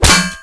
ric_metal-1.wav